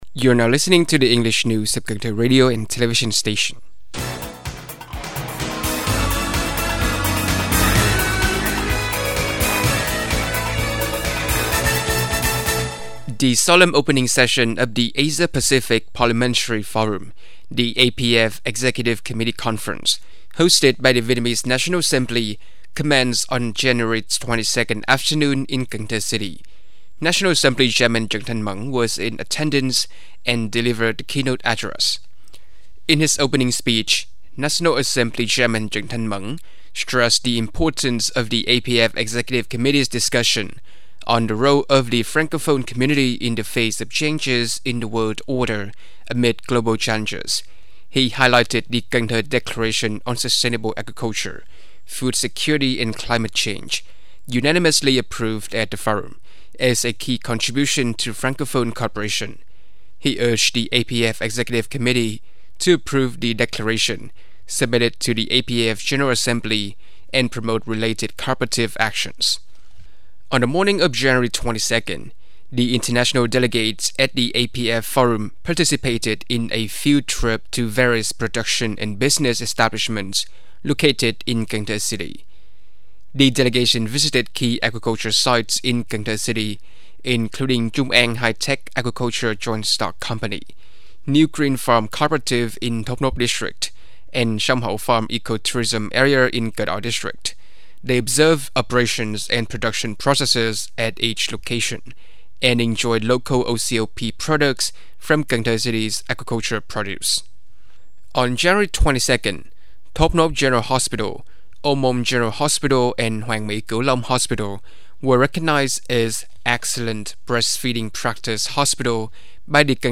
Bản tin tiếng Anh 23/1/2025